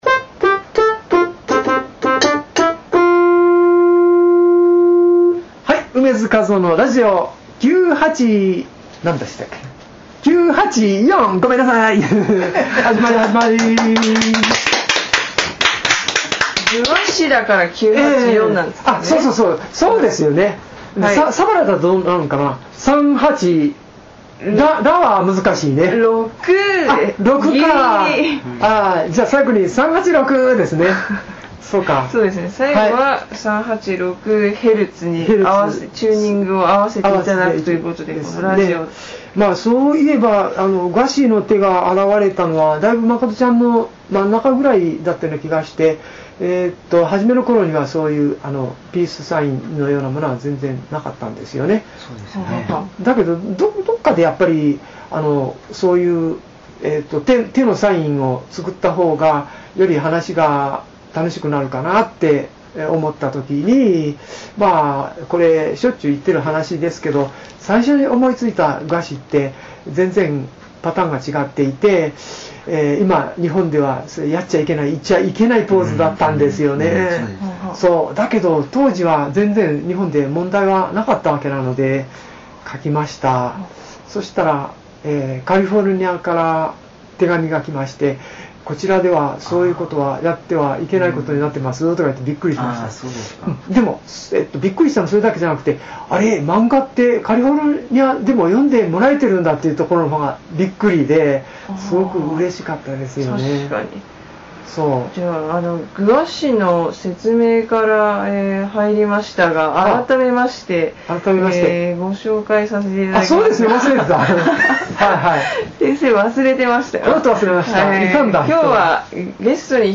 映画『赤んぼ少女』(山口雄大監督 公開時期未定)の完成を記念し、漫画『赤んぼ少女』について、楳図かずおが語ります！！楳図かずおがキーボードで奏でる愉快な旋律も必聴なのら！！